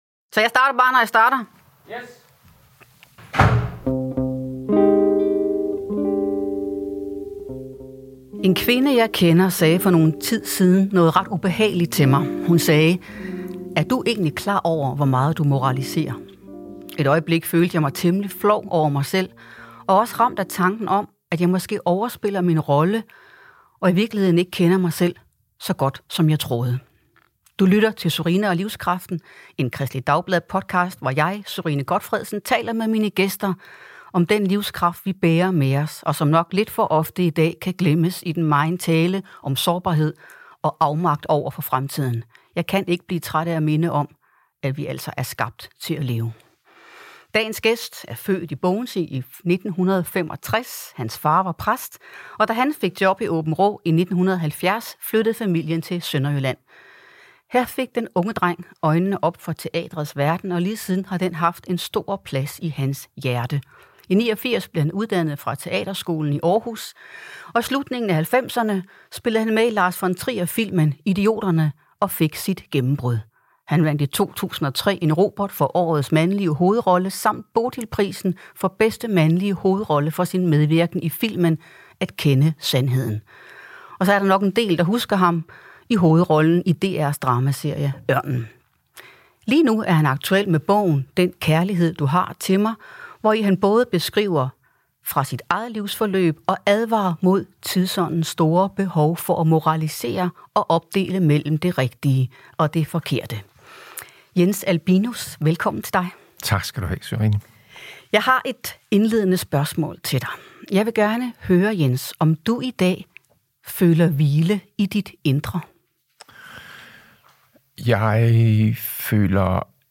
Skuespiller Jens Albinus voksede op med en far, der havde så meget på hjerte og var så optaget af at formulere sig så præcist som muligt, at hans sætninger aldrig sluttede. Når man hører Jens Albinus taler i dag, er det svært ikke at tænke det samme om ham. For man skal holde tungen lige i munden, når han taler.